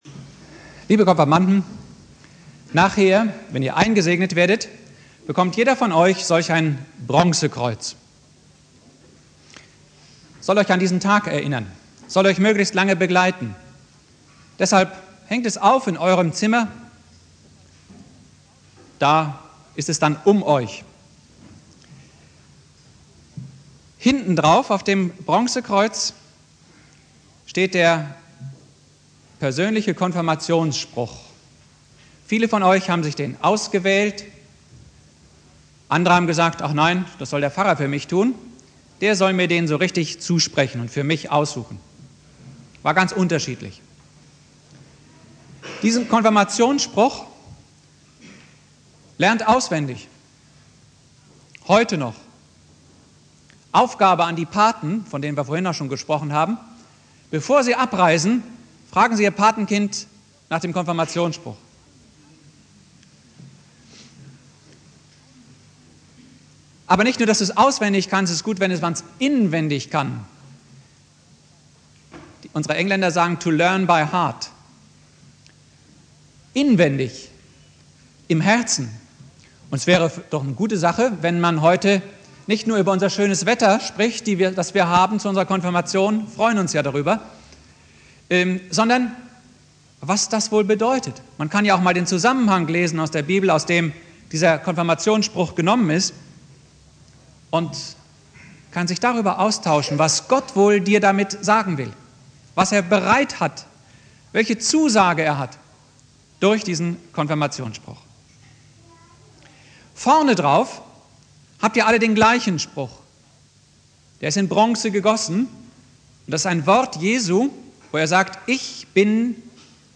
Predigt
"Ich bin der gute Hirte" - Wer ist Herr in meinem Leben? (Konfirmation Hausen) Bibeltext: Johannes 10,10-15 Dauer: 17:14 Abspielen: Ihr Browser unterstützt das Audio-Element nicht.